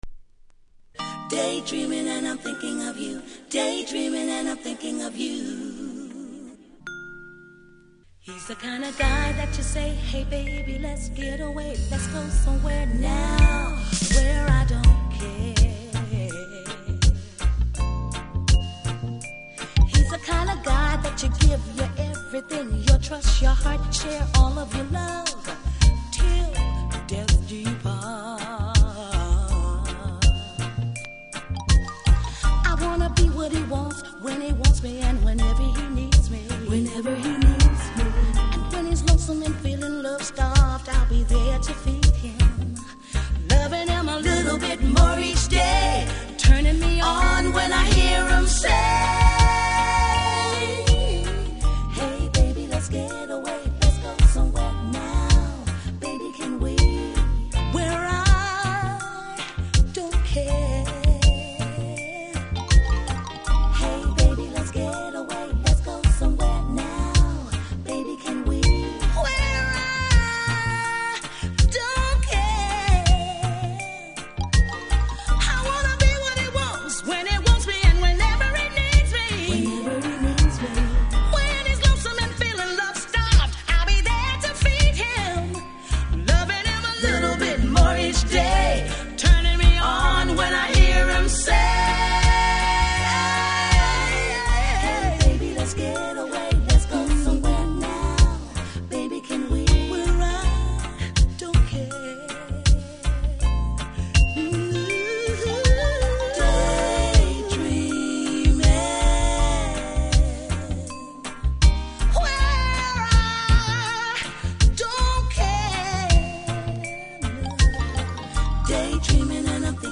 未発表テイクで初の7インチ化